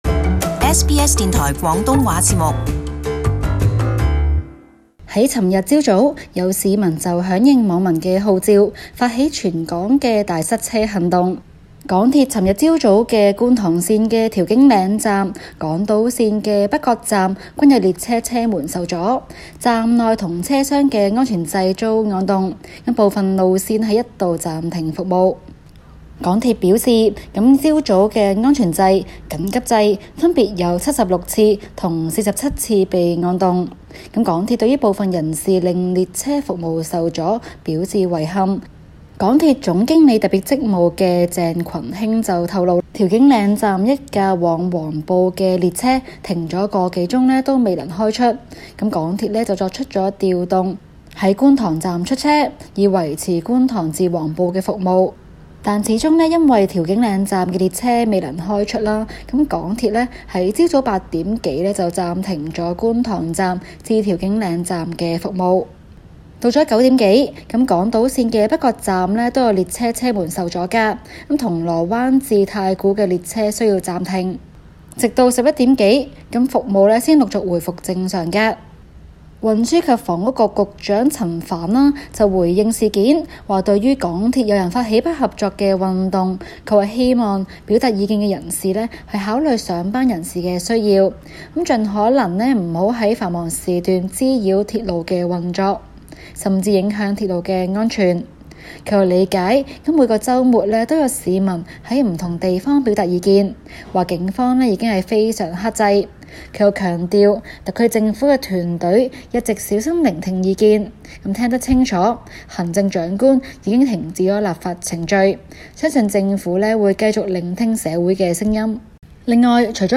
Source: REUTERS/Tyrone Siu SBS廣東話節目 View Podcast Series Follow and Subscribe Apple Podcasts YouTube Spotify Download (10.7MB) Download the SBS Audio app Available on iOS and Android 香港的抗議活動升級，港鐵成爲示威者宣洩不滿的對象。